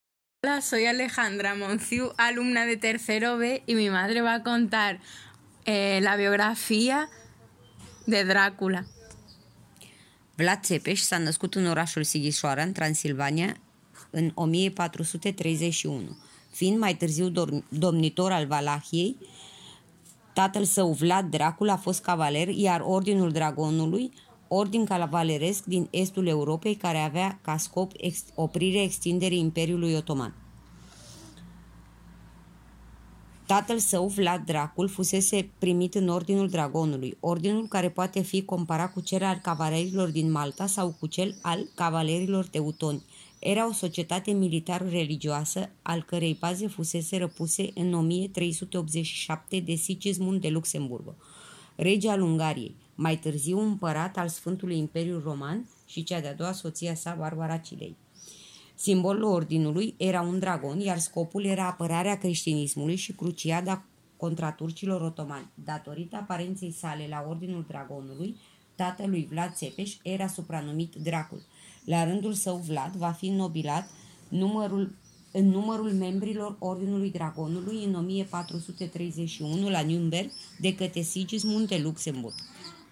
Este curso hemos propuesto una actividad a las familias del centro: contar historias de su país o de su zona geográfica y hacerlo en su idioma o con su acento particular.